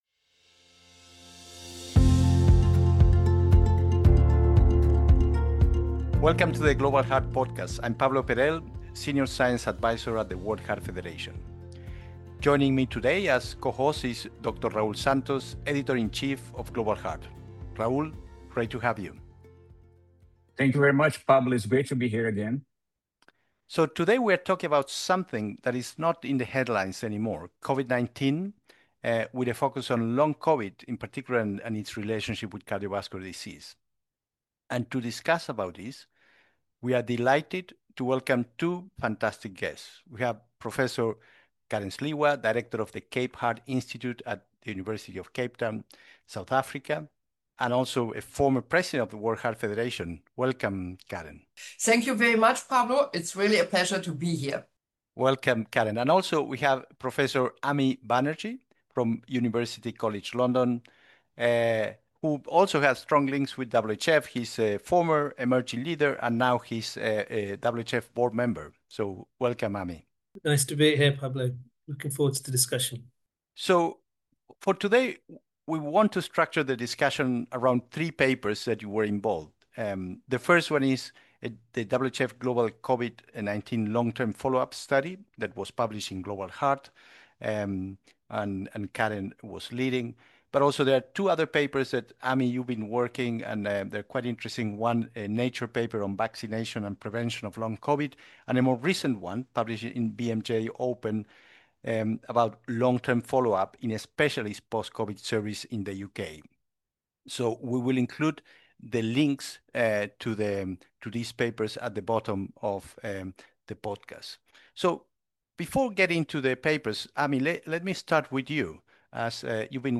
Listen to WHF experts discuss long COVID and CVD, including global research, symptoms, vaccination, and long-term health impacts.